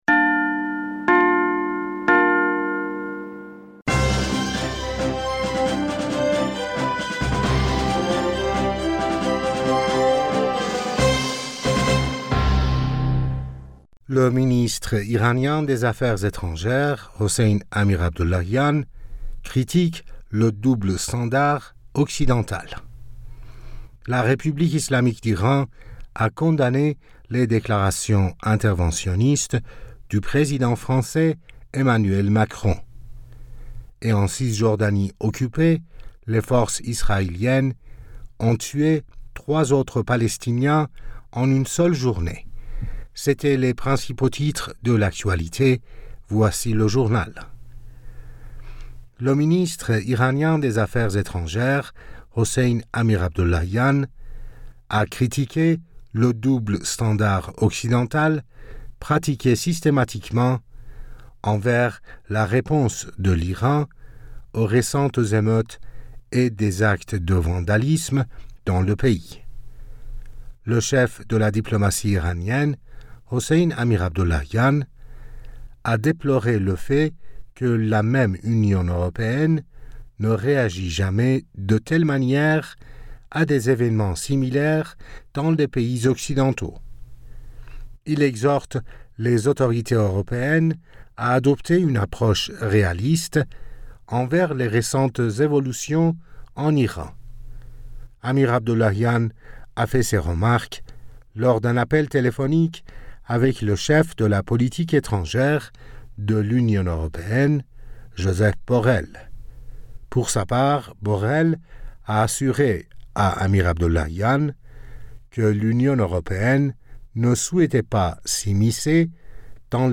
Bulletin d'information Du 15 Octobre